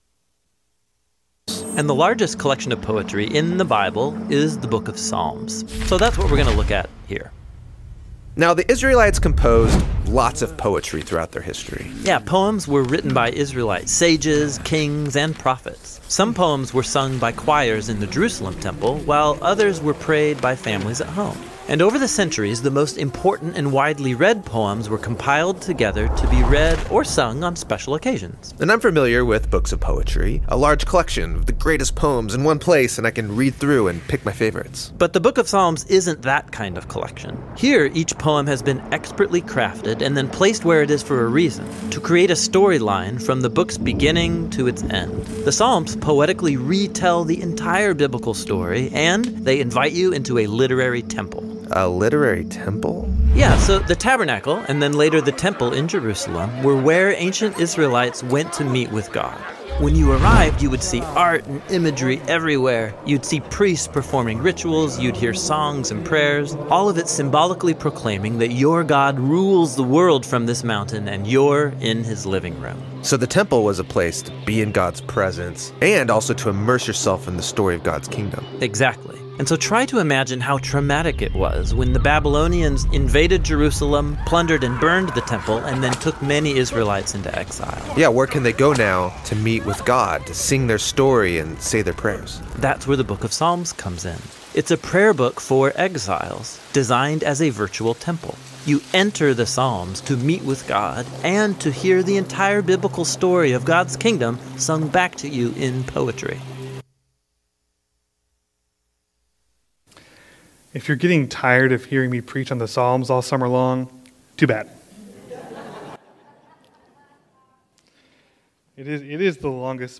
Sermons | Asbury Methodist Church